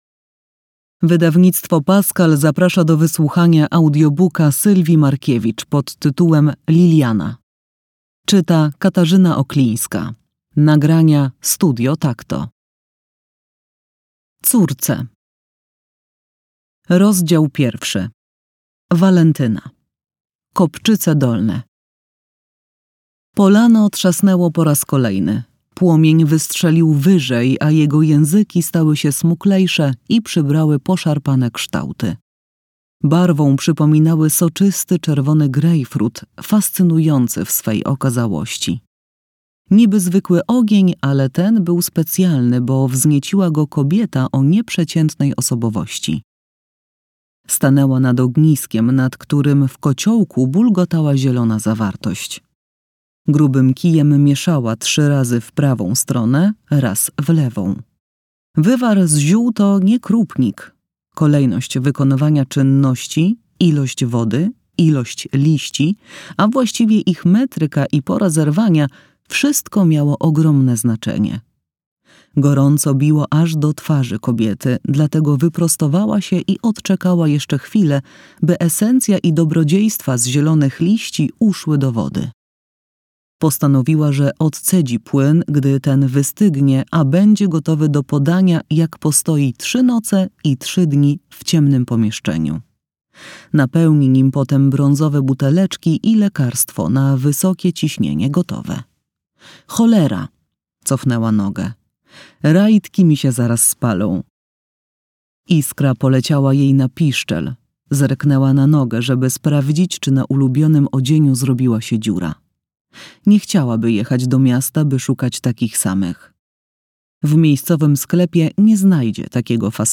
fragment audiobooka Liliana